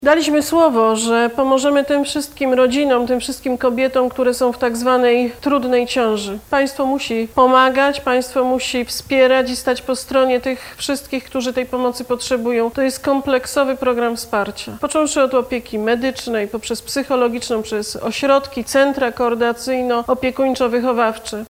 – Pomoc państwa w tym zakresie jest konieczna – powiedziała w nagraniu opublikowanym w internecie premier Beata Szydło.